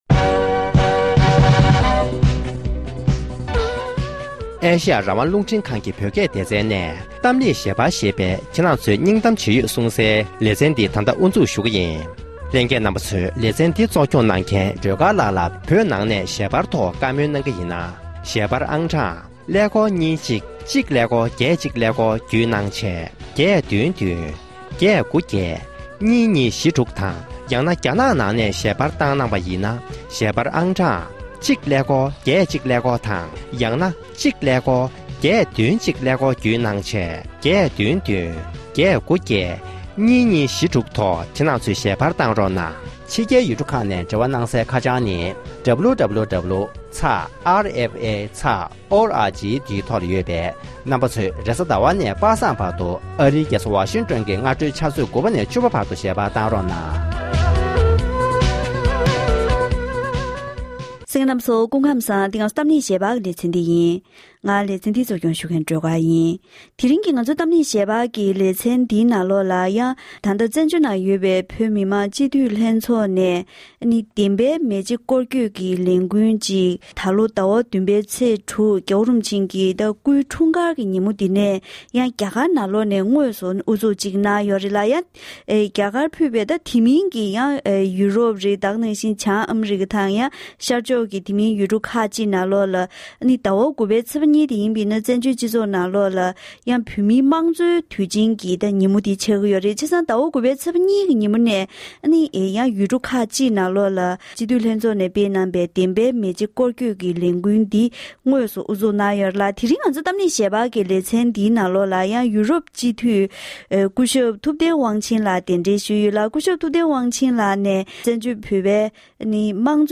བཀའ་མོལ་ཞུས་པ་ཞིག